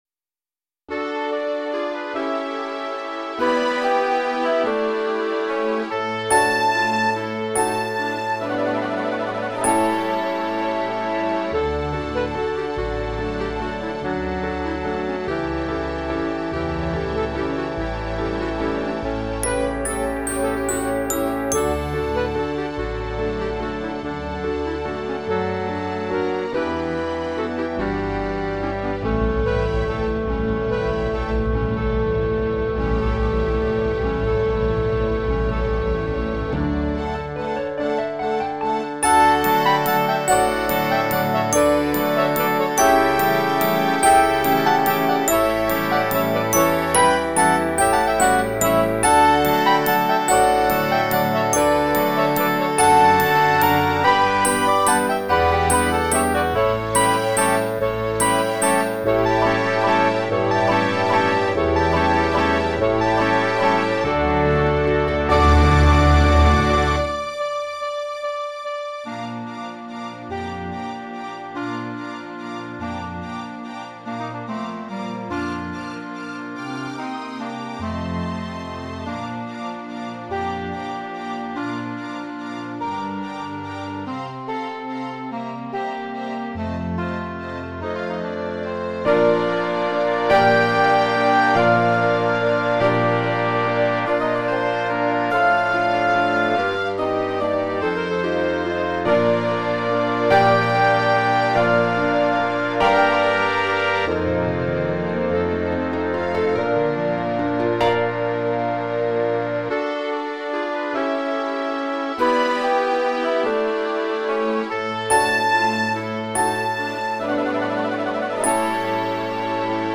SYNTHESISED RECORDINGS LIBRARY
Orchestra